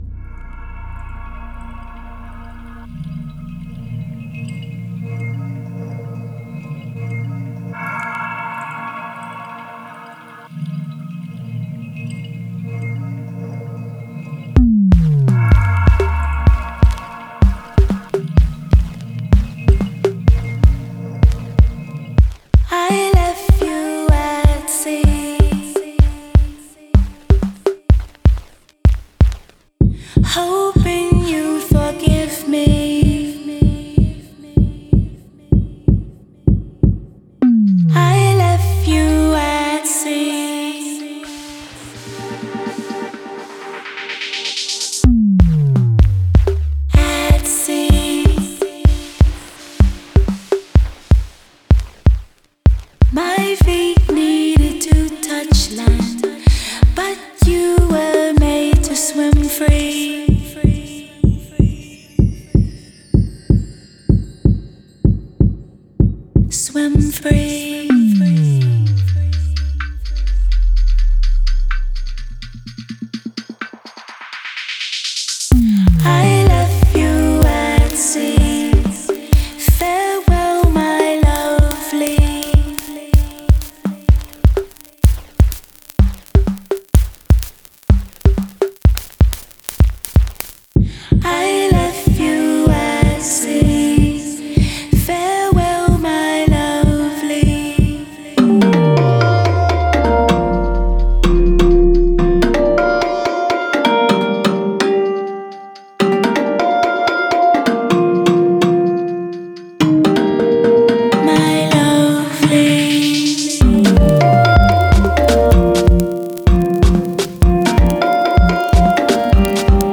Жанр: Soul.